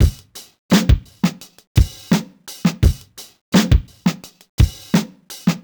Index of /musicradar/sampled-funk-soul-samples/85bpm/Beats
SSF_DrumsProc2_85-03.wav